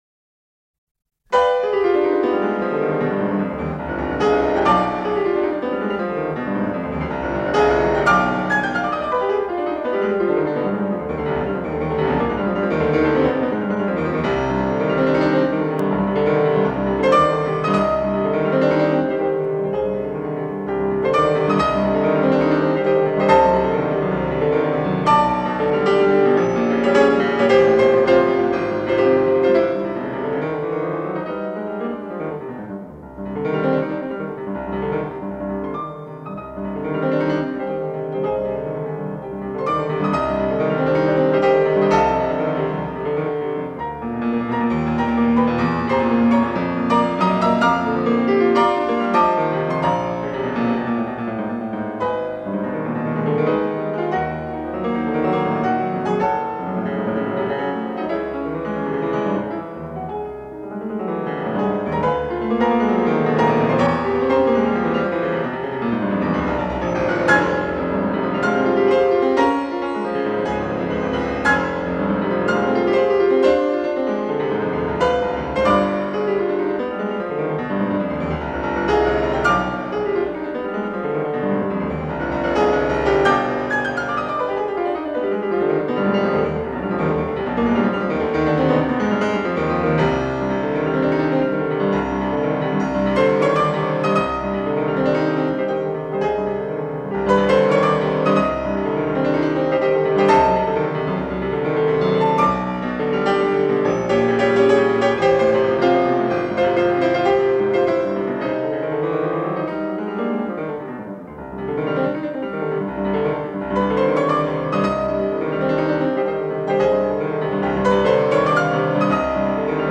0172-钢琴名曲波兰圆舞曲.mp3